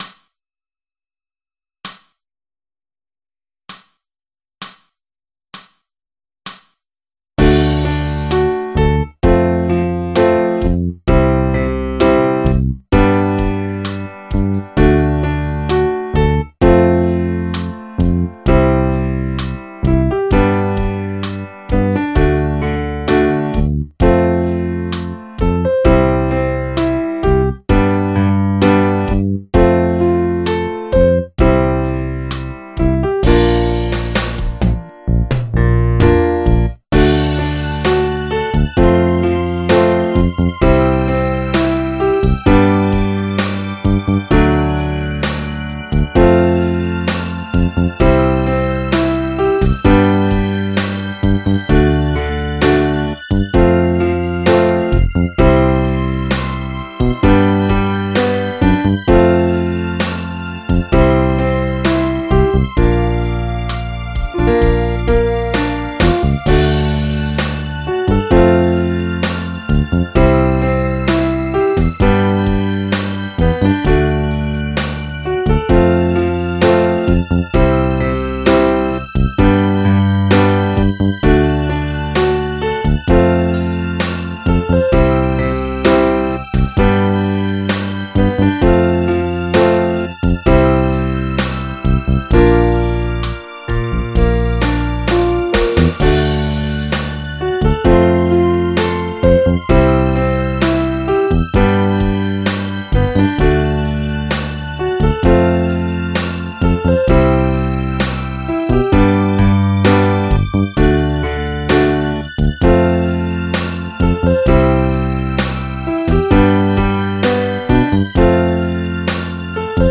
akkoorden van het refrein